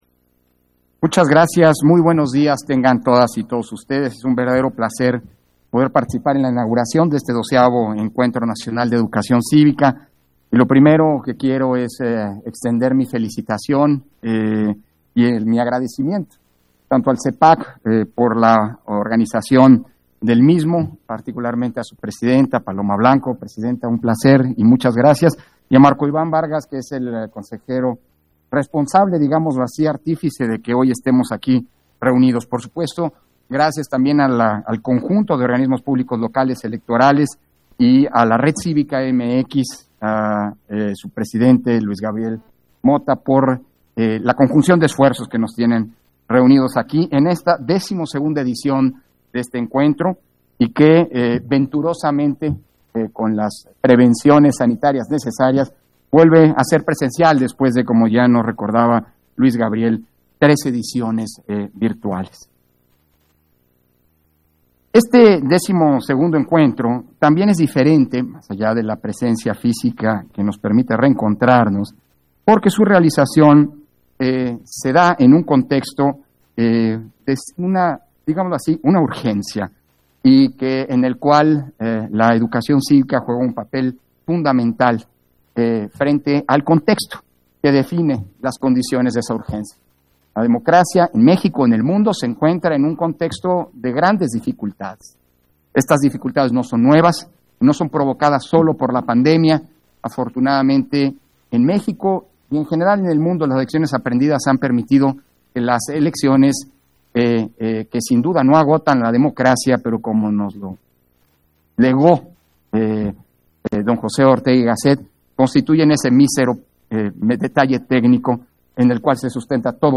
160322_AUDIO_INTERVENCIÓN-CONSEJERO-PDTE.-CÓRDOVA-INAUGURACIÓN-DEL-XII-ENCUENTRO-NACIONAL-DE-EDUCACIÓN-CÍVICA - Central Electoral